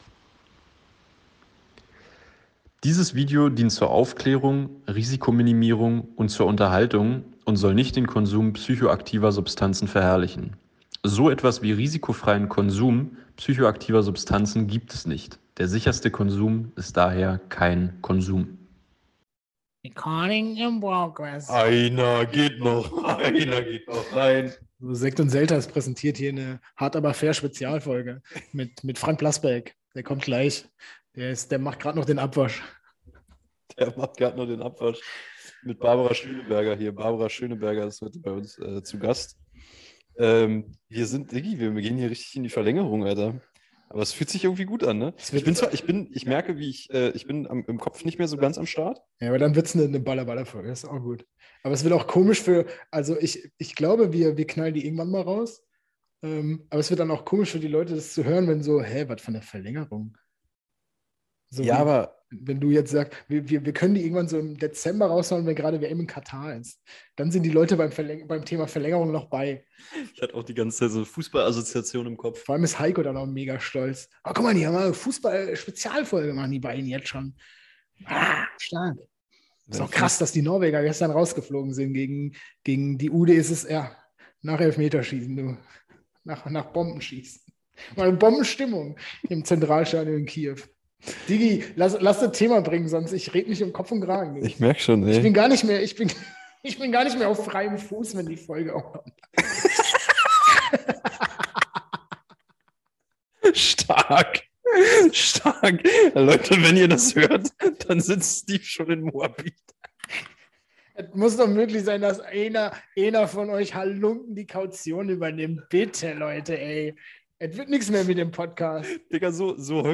Ab der kommenden Episode nehmen wir wieder, wie gewohnt, in unseren privaten Saunen auf, weshalb der Ton dann auch wieder Profi-Ton sein wird.